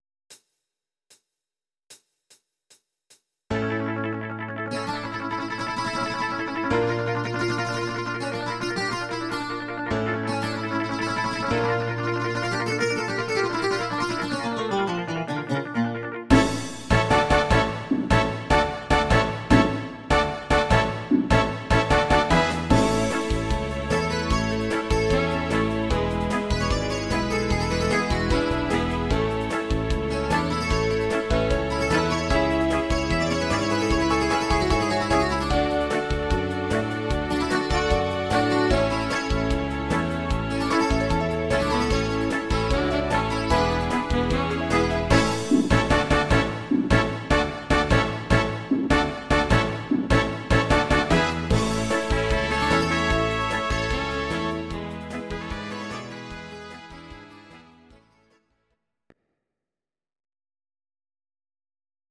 Audio Recordings based on Midi-files
Pop, Oldies, 1960s